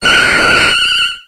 Grito de Vileplume.ogg
Grito_de_Vileplume.ogg